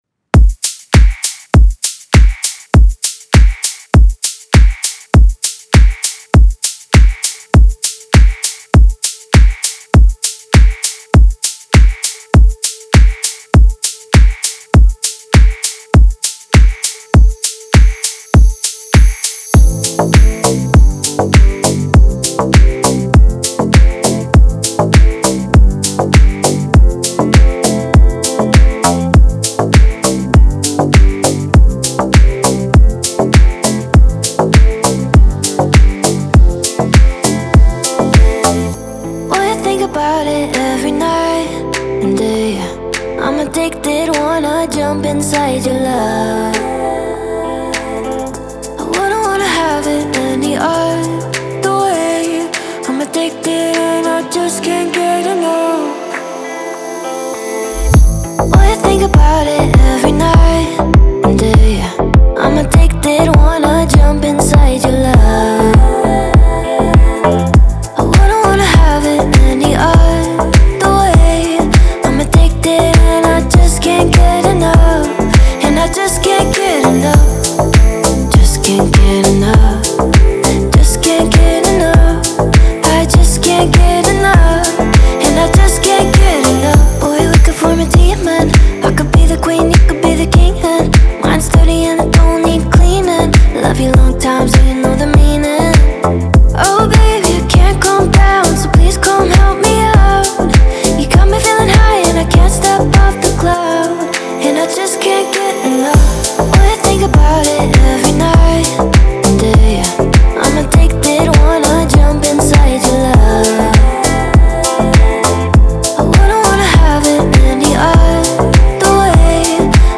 танцевальная музыка